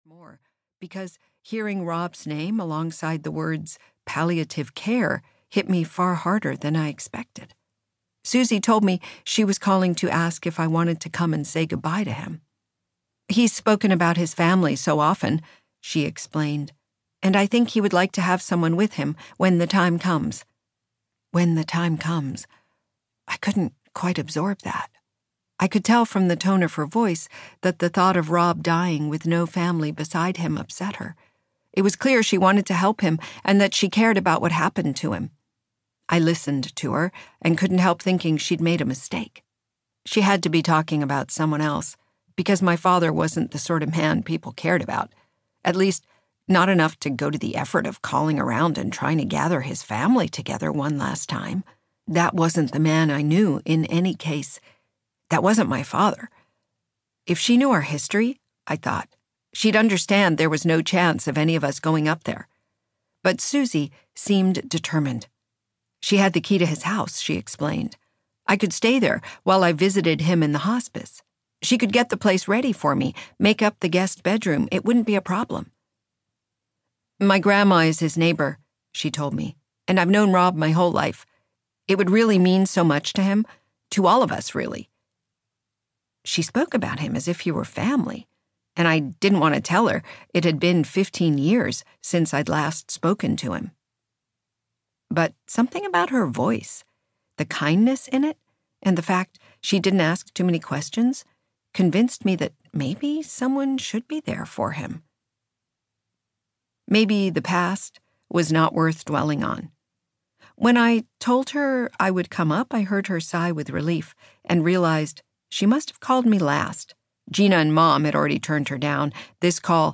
The Vanishing Child (EN) audiokniha
Ukázka z knihy